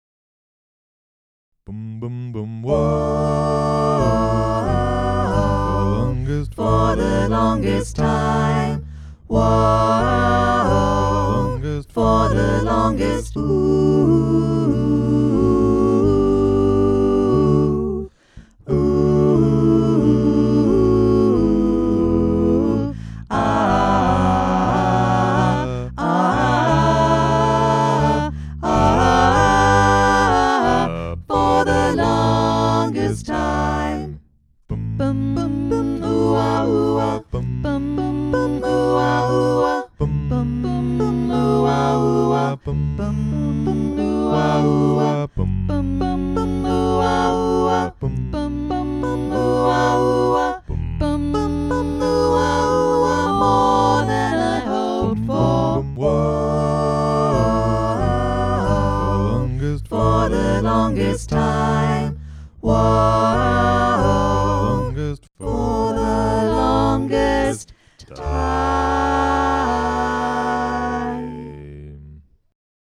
Vox Populi Choir is a community choir based in Carlton and open to all comers.
Longest_Time_All_Parts_Together.mp3